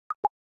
17_Voice_Success.ogg